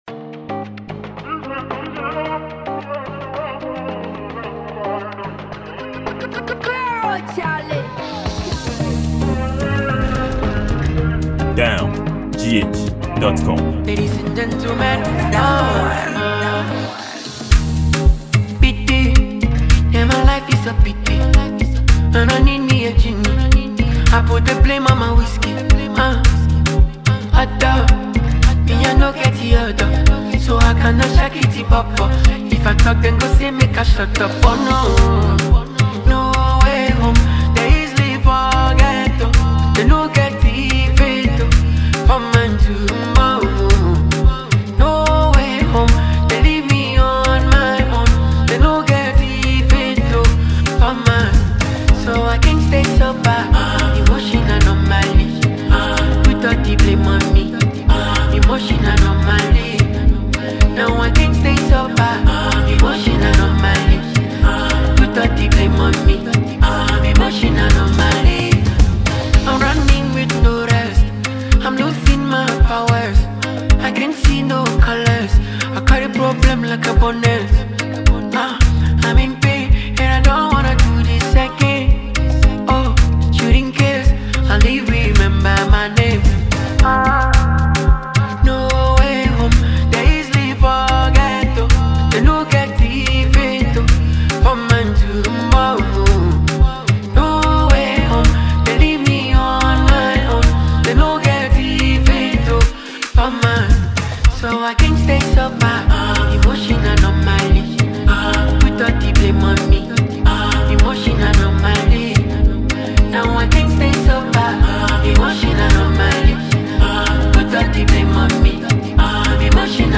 Ghanaian song producer, highlife singer and songwriter